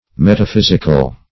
Metaphysical \Met`a*phys"ic*al\, a. [Cf. F. m['e]taphysique.